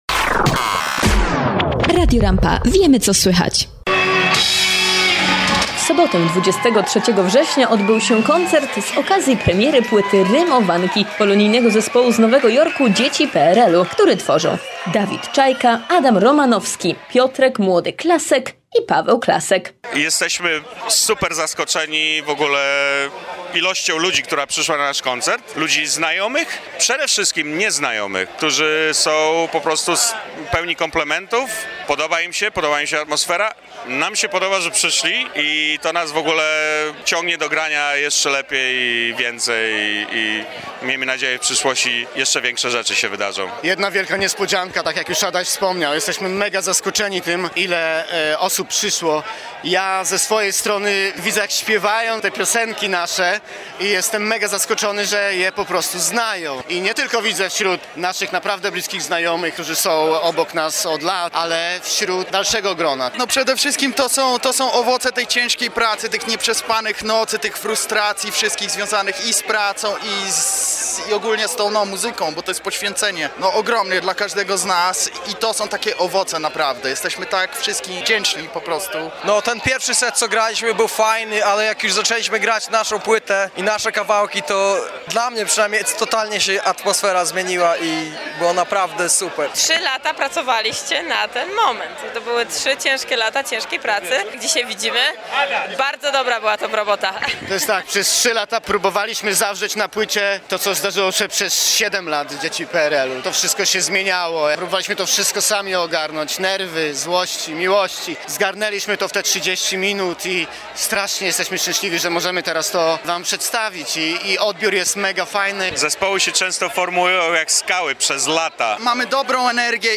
W koncercie, który miejsce miał w klubie PaperBox na Brooklynie, udział wzięło 200 osób.
Oprócz swoich utworów, grupa wykonała kilka coverów, dając świetny pokaz muzyki rockowej. Zapraszamy do wysłuchania relacji z koncertu, z której dowiecie się jak oceniają go sami muzycy, zaraz po zejściu ze sceny.